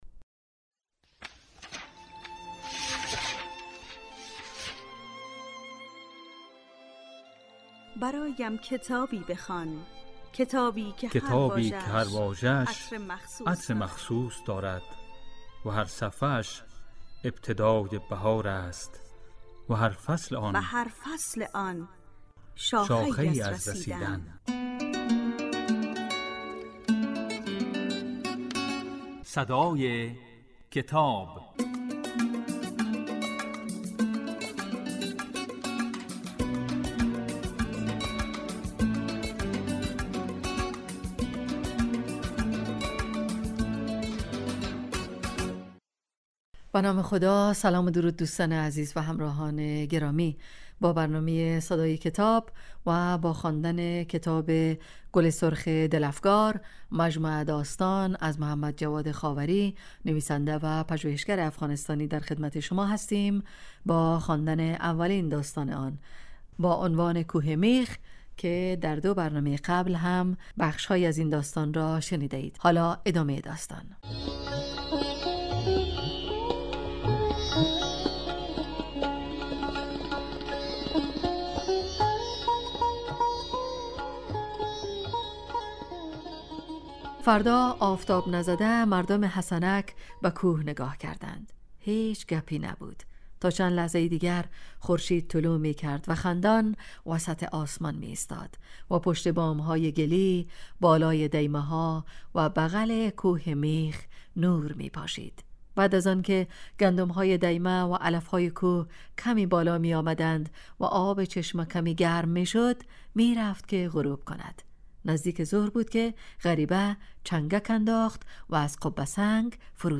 این برنامه کتاب صوتی است و در روزهای یکشنبه، سه شنبه و پنج شنبه در بخش صبحگاهی پخش و در بخش نیمروزی بازپخش می شود.